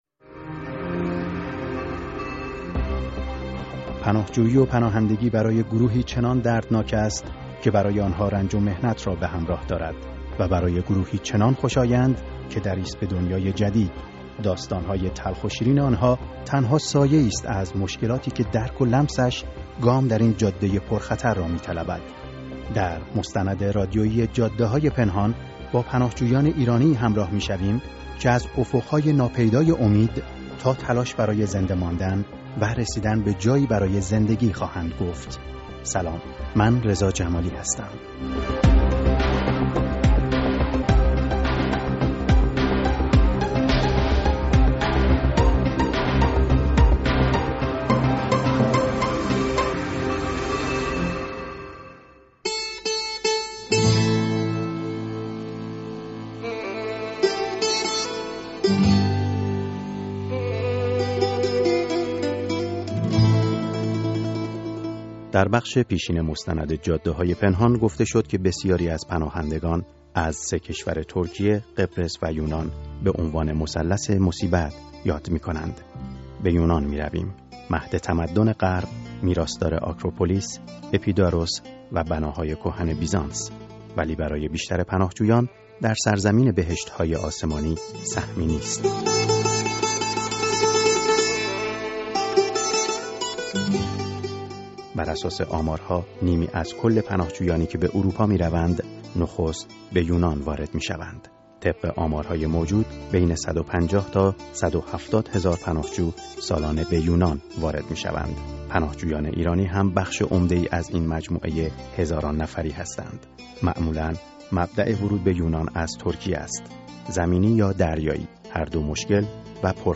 مستند رادیویی جاده‌های پنهان: بخش دوازدهم